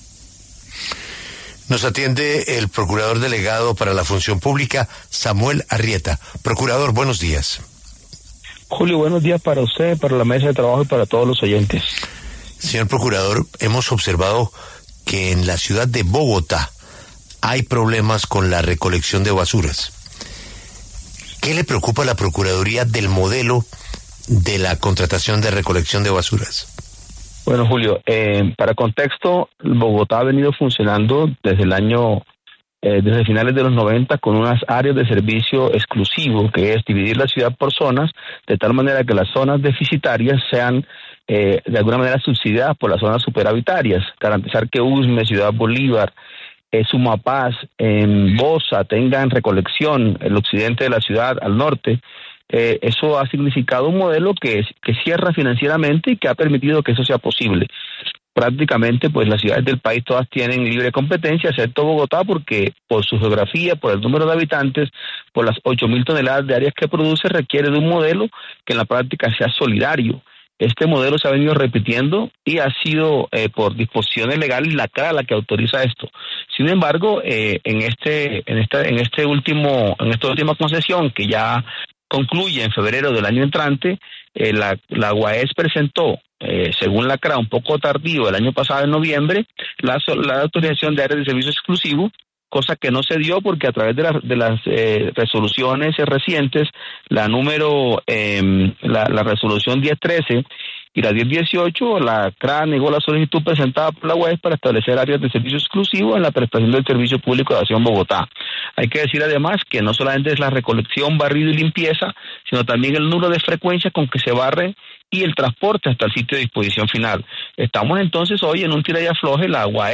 En entrevista con La W, el procurador delegado para la Función Pública, Samuel Arrieta, expresó la preocupación del ente de control por la situación de basuras y el modelo de recolección de Bogotá.